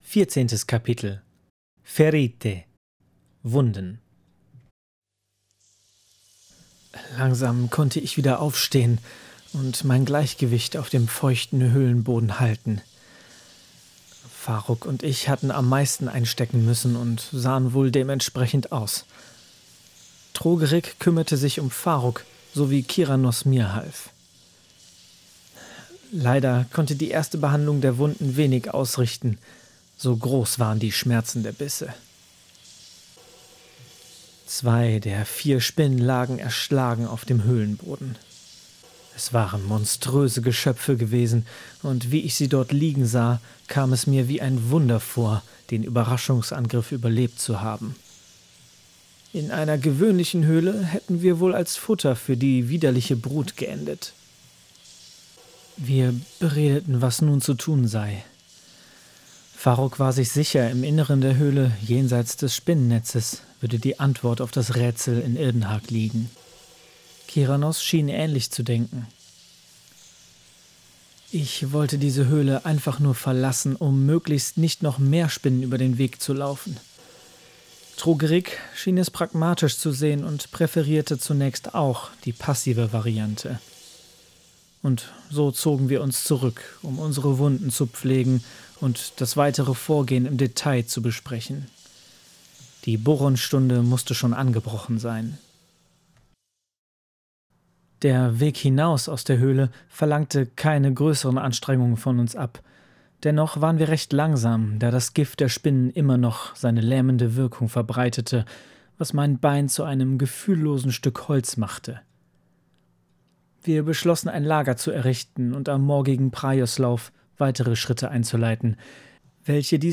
Die Calleano-Chroniken – Ein Hörbuch aus der Welt des schwarzen Auges